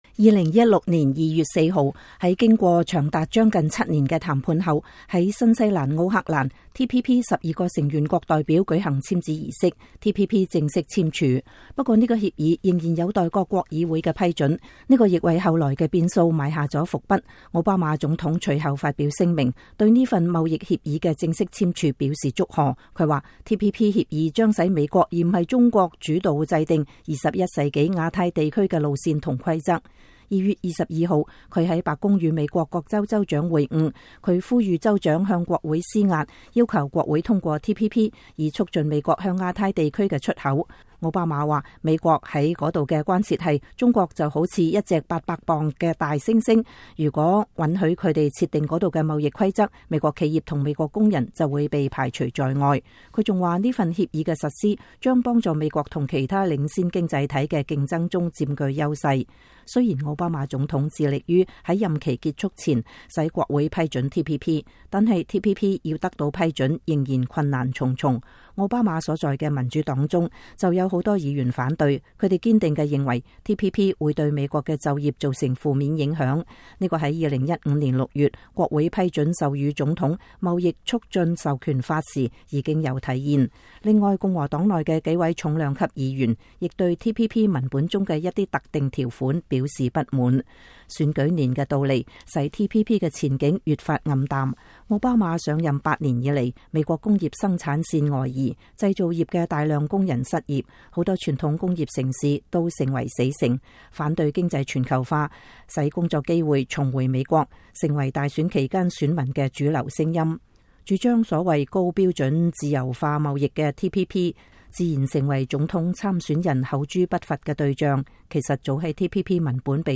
年終報導- 2016年 “煉獄”中的TPP